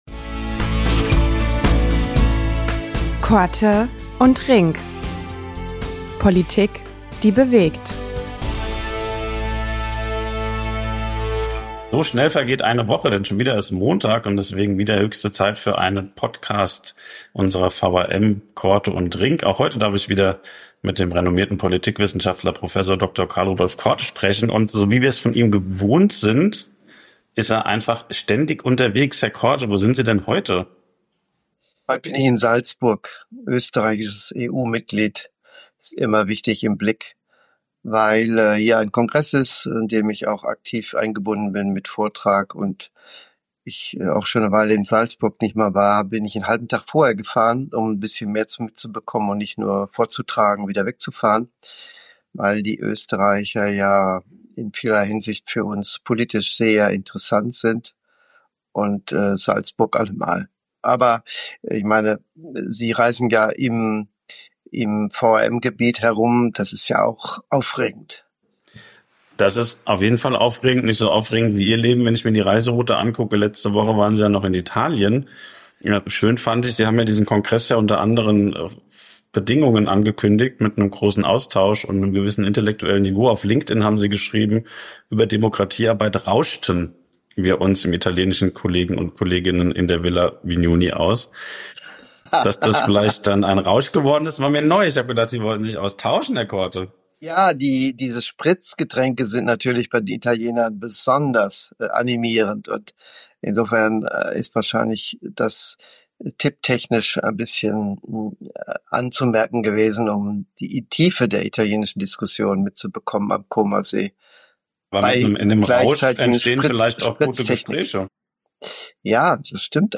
Nach abgeschlossener Italien-Reise nun aus Salzburg zugeschaltet